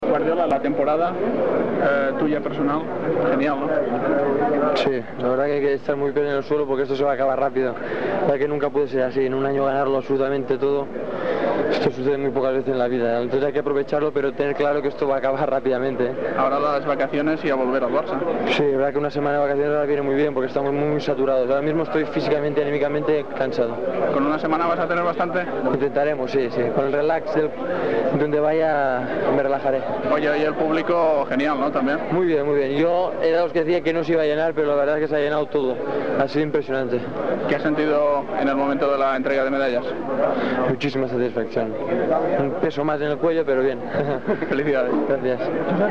Entrevista al jugador de futbol Pep Guardiola després del partit de la final de futbol dels Jocs Olímpics de Barcelona 92 celebrat al Nou Camp.
Esportiu